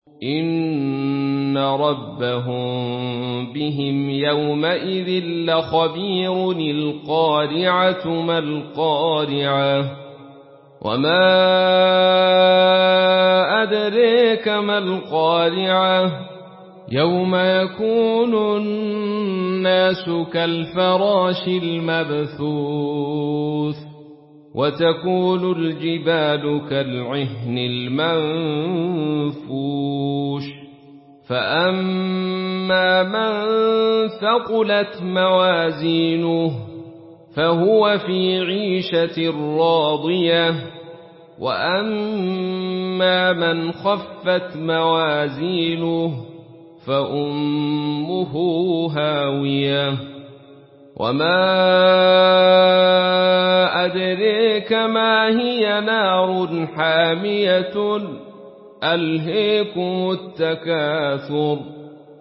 Surah আল-ক্বারি‘আহ্ MP3 in the Voice of Abdul Rashid Sufi in Khalaf Narration
Murattal